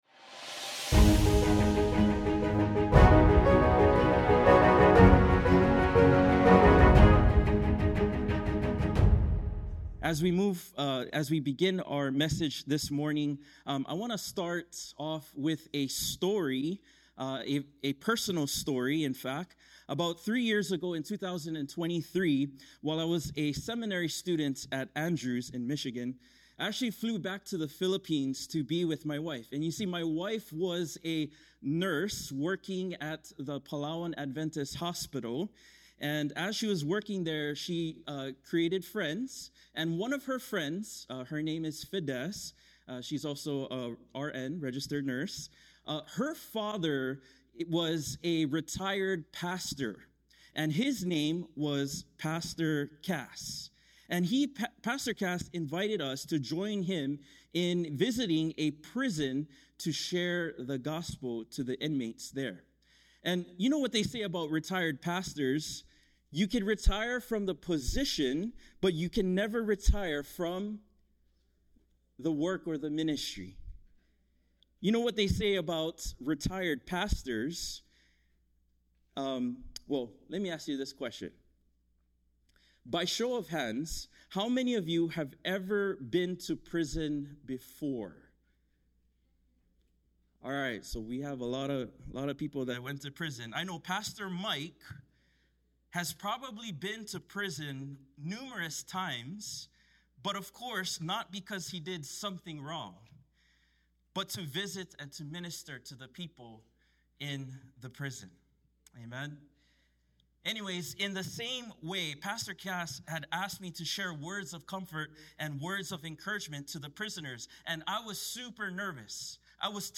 Sermon Archive – Sacramento Central Seventh-day Adventist Church